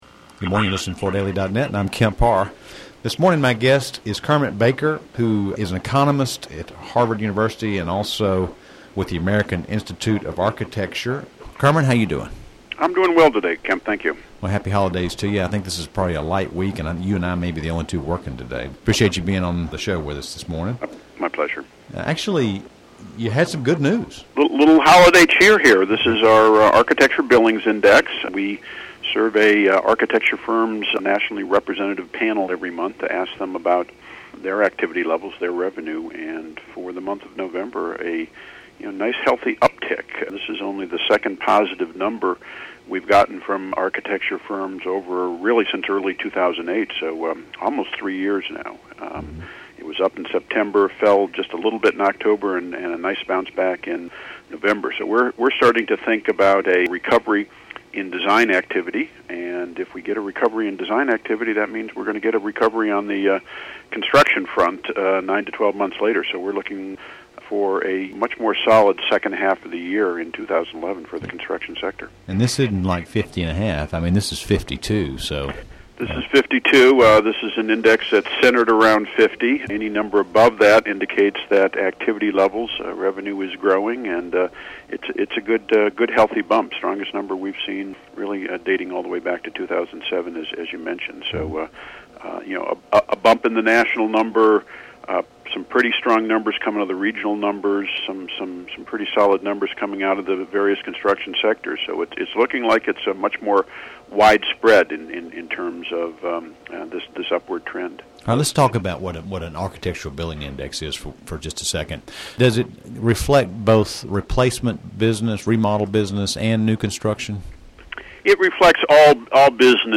Listen to the interview to hear more about the magnitude of a 52 score and specific regional and market sector detail.